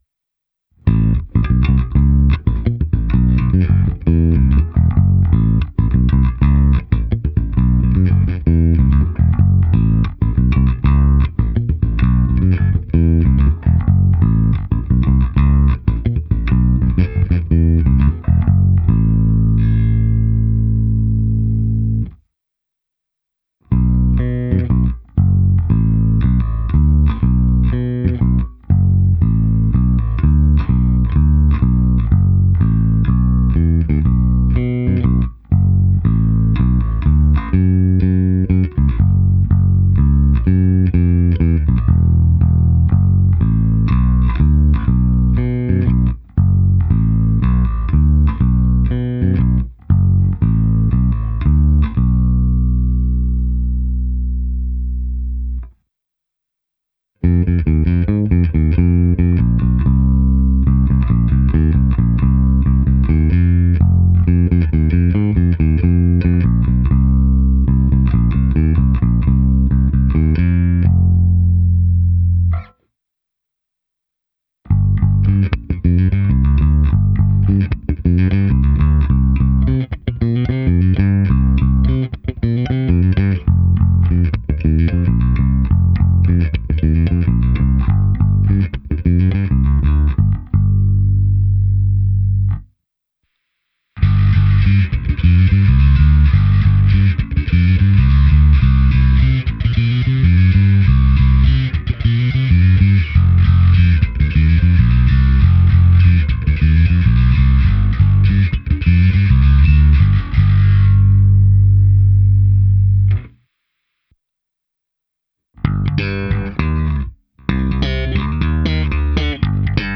Abych simuloval, jak asi hraje baskytara přes aparát, použil jsem svůj pedalboard s preampem Darkglass Harmonic Booster, kompresorem TC Electronic SpectraComp a preampem se simulací aparátu a se zkreslením Darkglass Microtubes X Ultra. V nahrávce jsem použil i zkreslení a slapu.
Ukázka se simulací aparátu